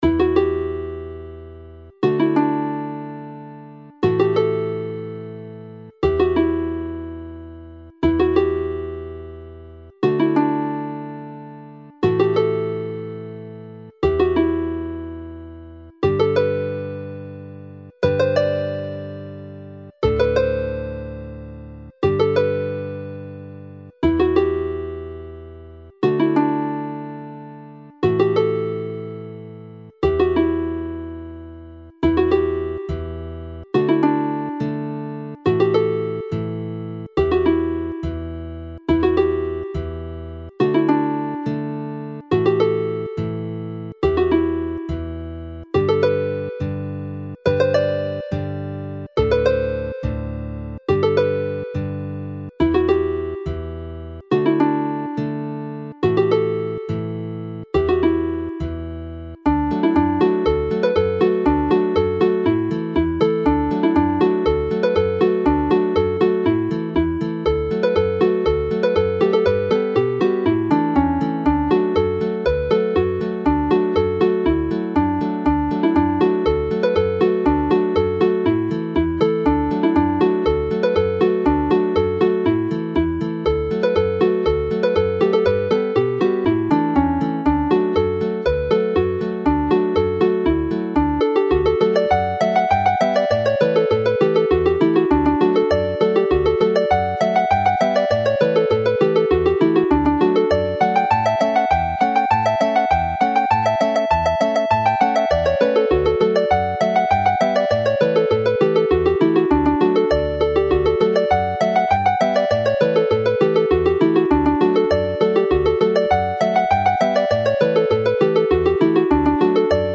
The haunting air Lorient which starts this set can be heard around the streets of Lorient during the festival as a tuning-up routine used in warming up by the pipers in Breton Bagapipe bands.
Hela'r Geinach (Hunting the Hare) is clearly another pipe tune which is more lively whilst Aden y Frân Ddu (the Black Crow's Wing) is livelier still.